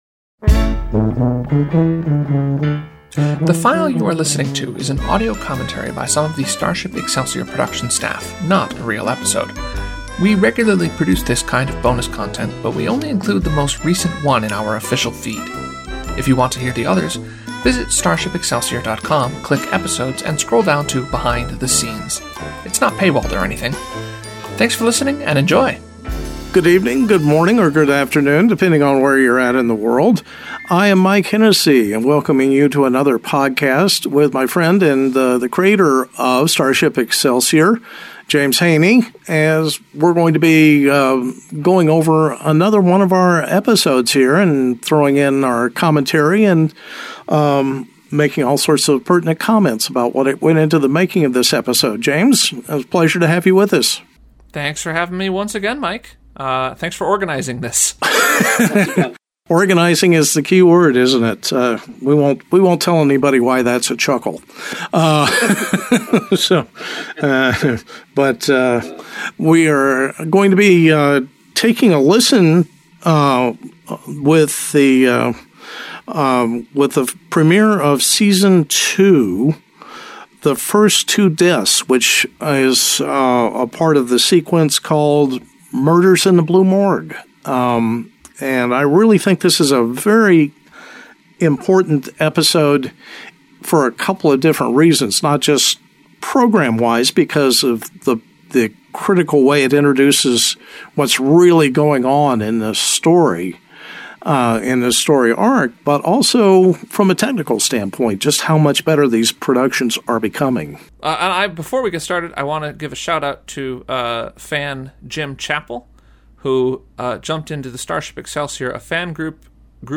Commentary: The First Two Deaths.